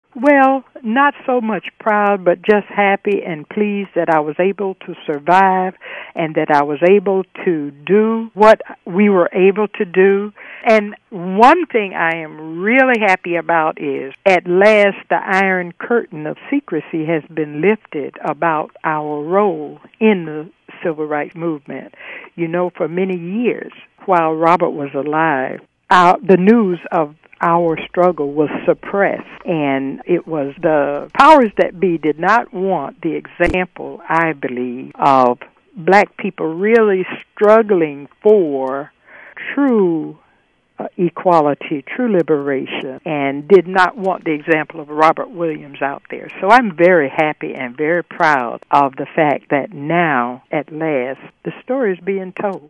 from an interview